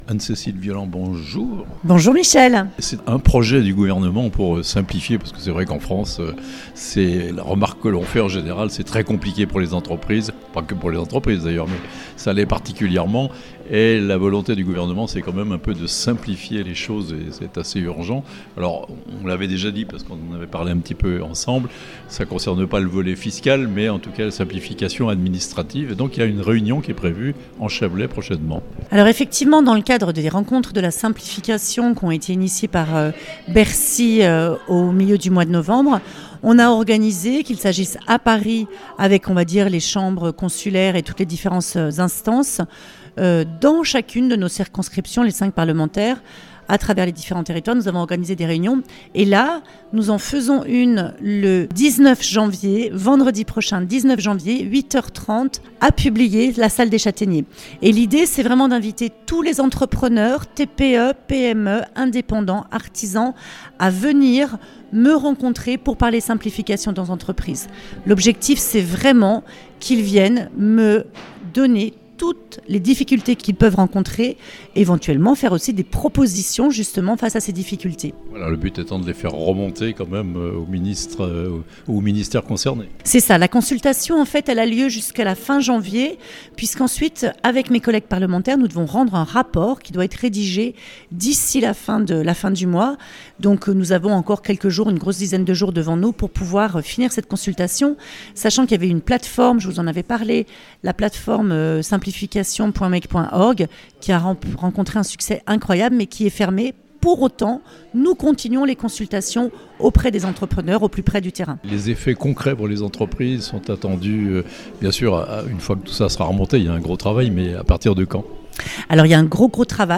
Publier : la Députée du Chablais organise un petit-déjeuner de travail avec les chefs d'entreprises le 19 janvier (interview)